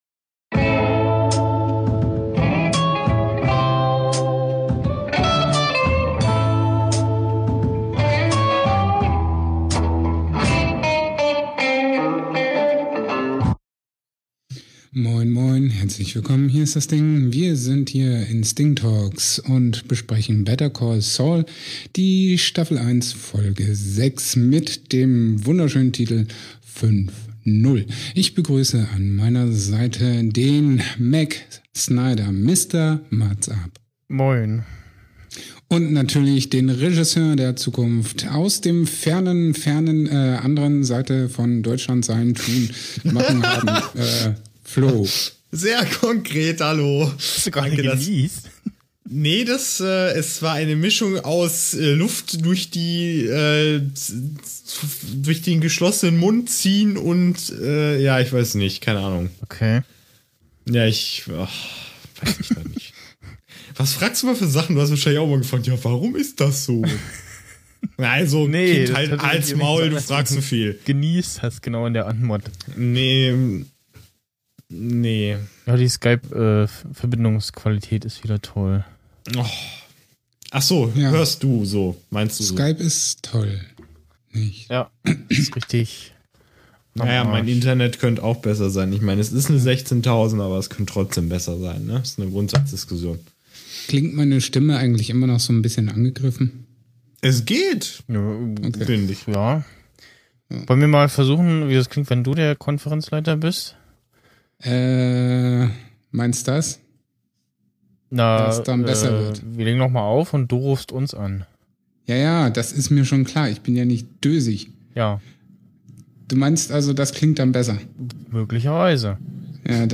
Trotz Skype versuchen wir uns über die 6. Folge von Better Call Saul zu unterhalten, in der der Hauptdarsteller selbst nur sehr kurz auftaucht und diesmal der gute Michael "Mike" Ehrmantraut im Vordergrund steht.